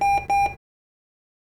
beep_error.wav